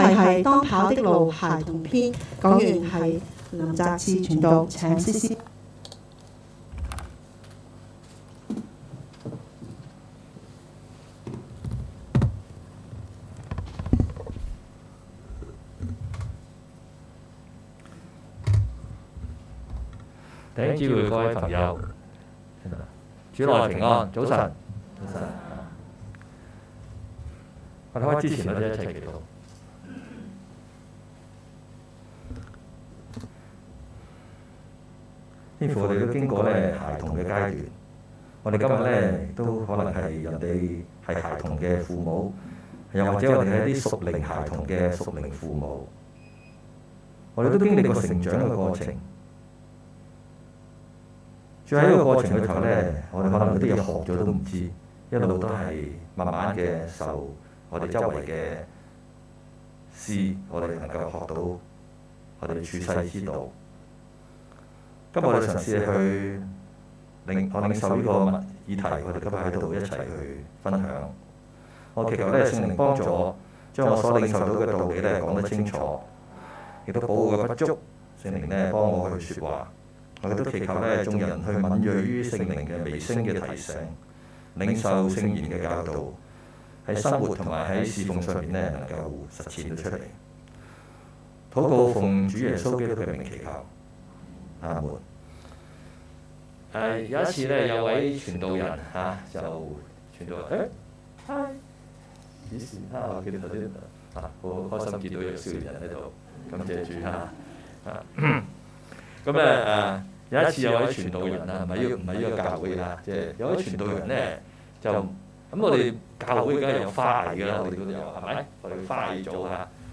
2021 年 8 月 7 日及 8 日崇拜
講道